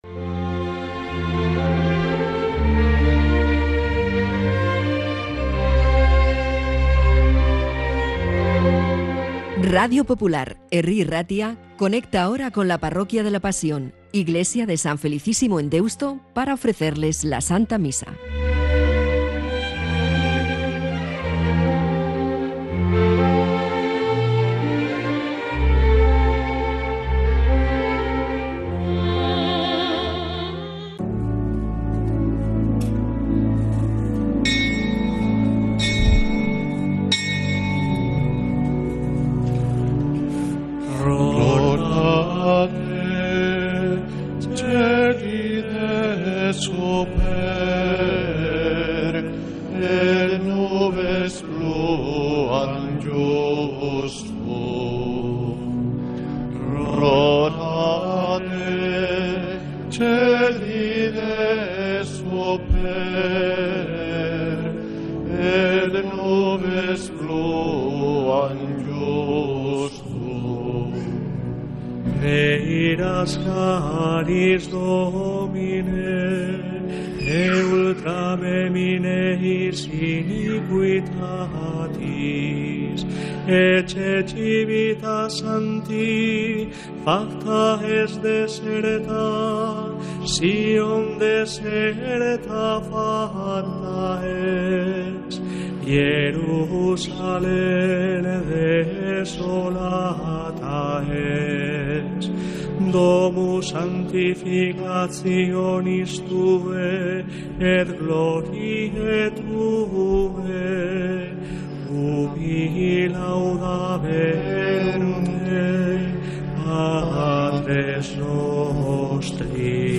Santa Misa desde San Felicísimo en Deusto, domingo 21 de diciembre de 2025